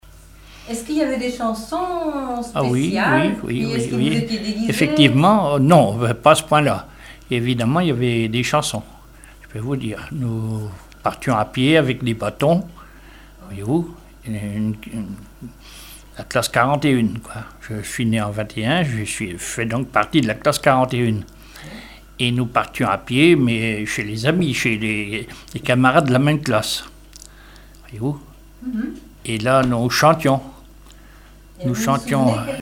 chanteur(s), chant, chanson, chansonnette
Catégorie Témoignage